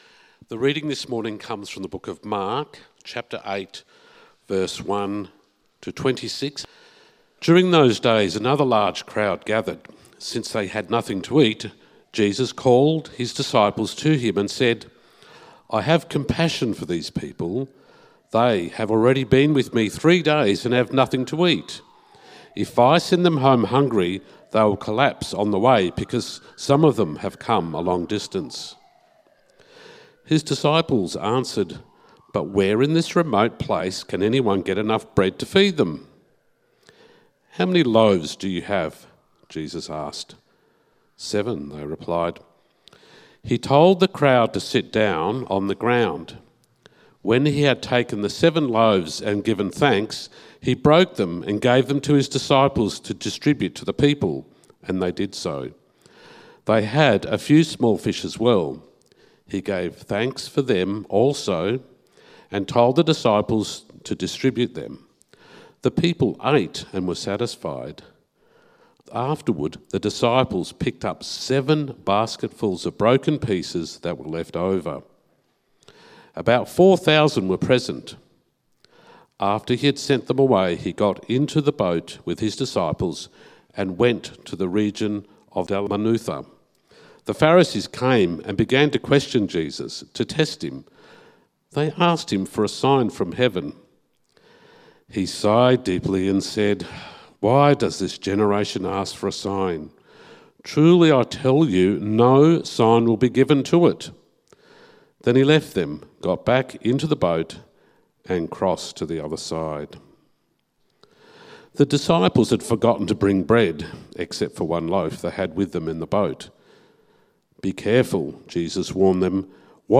Meet Jesus Sermon outline